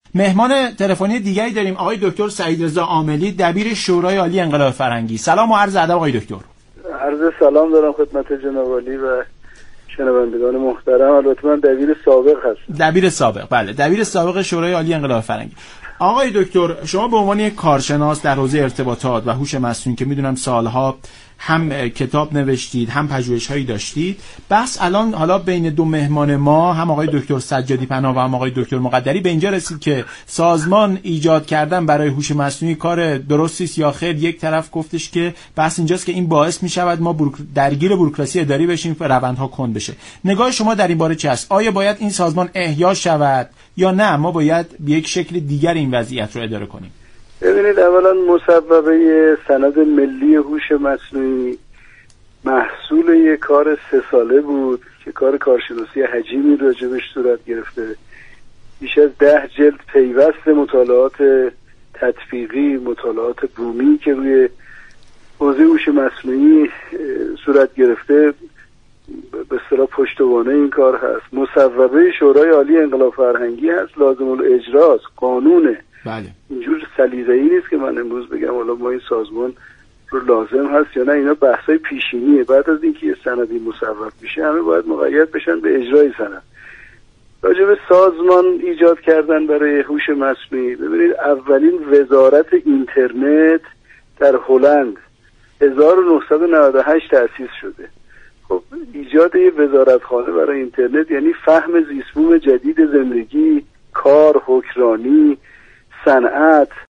دبیر سابق شورای عالی انقلاب فرهنگی در برنامه ایران امروز گفت: سند‍ملی‌هوش‌مصنوعی مصوبه شورای عالی انقلاب فرهنگی است و باید اجرا شود.